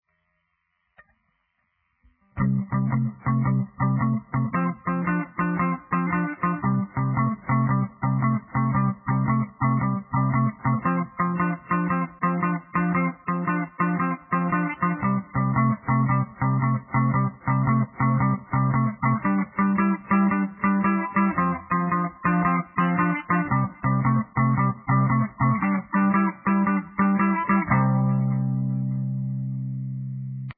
The characteristic signature of the shuffle is a long-short rhythmic pattern that cycles through an entire passage of music.
Blues Shuffle In C
higher up in the neck